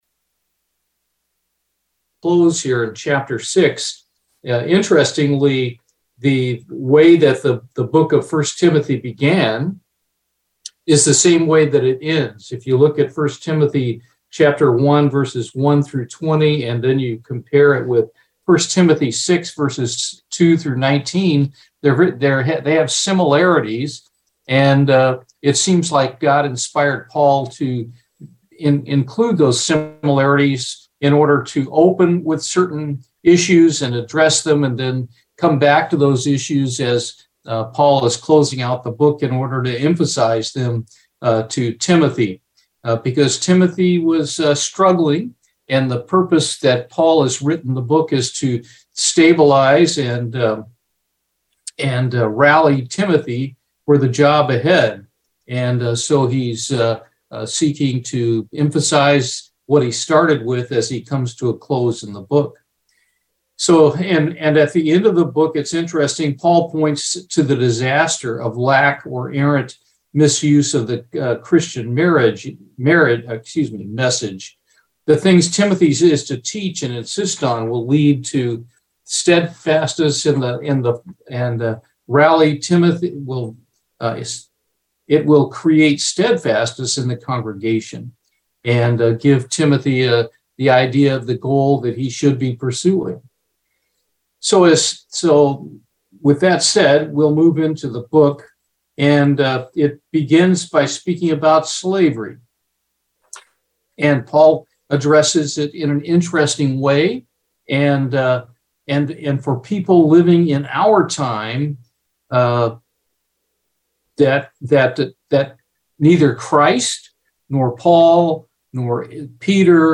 Bible Study, I Timothy 6